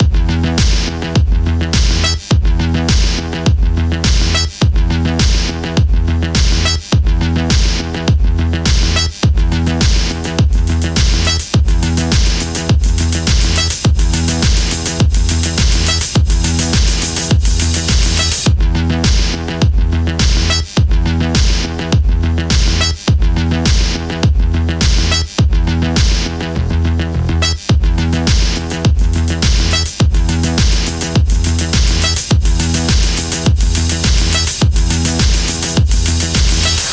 • Качество: 211, Stereo
без слов